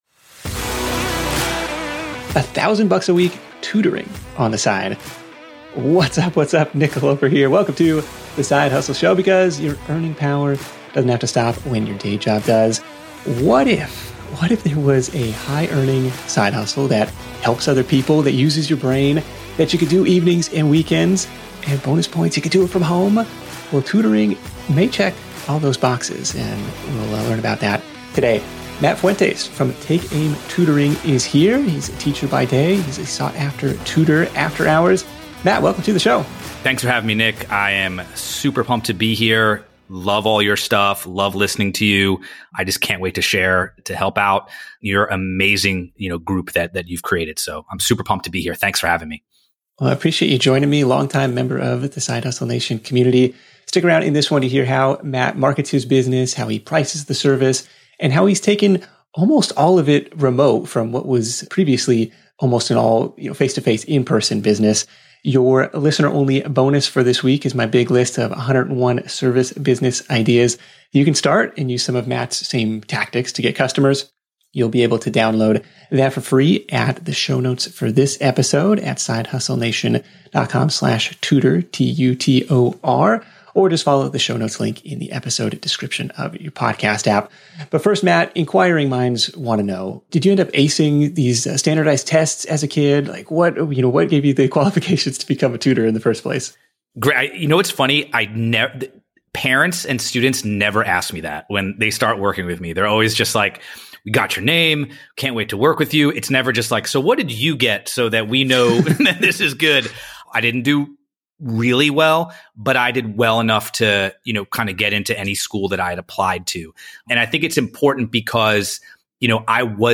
In this post and podcast interview, he breaks down the steps he took to build a $1000 a week tutoring business.